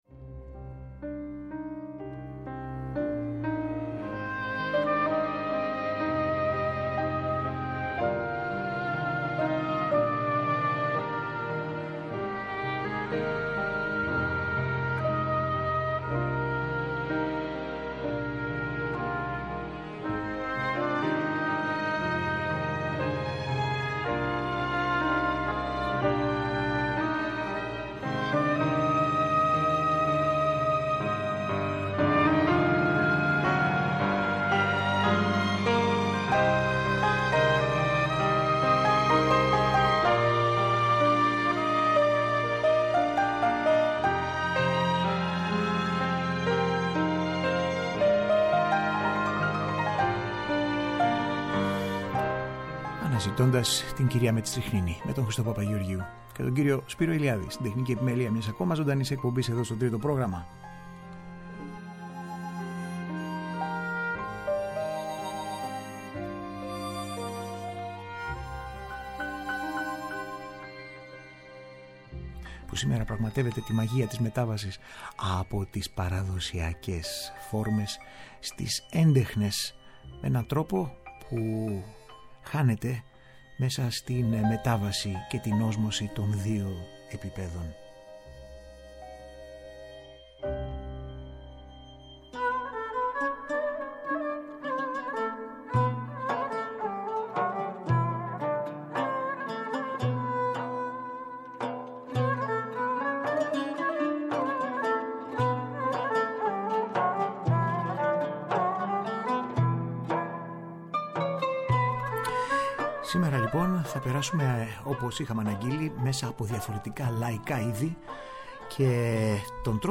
Οι Λαϊκές Μουσικές δοσμένες με λόγιο τρόπο. Τραγούδια και μελωδίες από όλο τον κόσμο που περνούν μέσα από το φίλτρο της έντεχνης μουσικής κάθε εποχής και συνθέτη ώστε επεξεργασμένες να επιστρέφουν κάθε φορά ανανεωμένες στην κοινή συνείδηση.